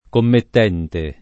commettente [ kommett $ nte ]